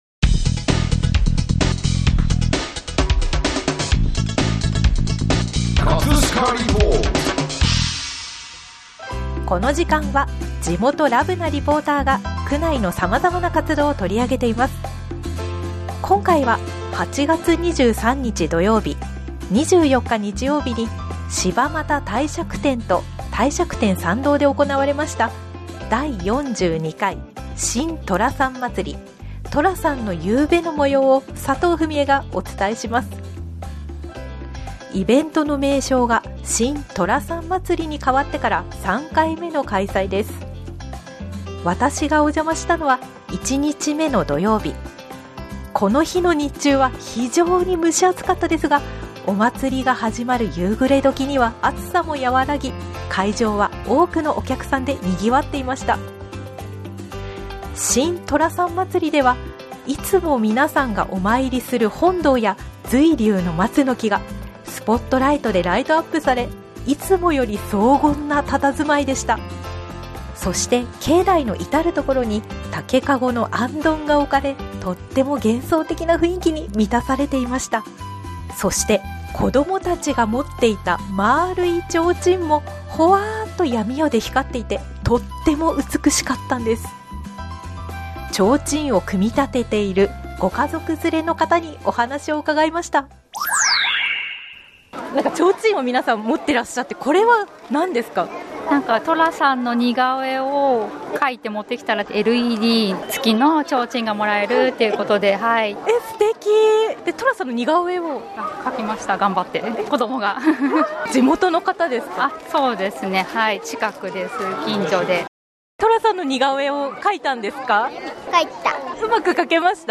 幻想的な帝釈天に、美しい弦楽器の音色が流れていました…♪ 演奏していたのは「葛飾、街の音楽隊」の皆さんです。
ライトアップの美しさを音でお届け♪葛飾リポート、ぜひお聴きください！